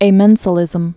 (ā-mĕnsə-lĭzəm)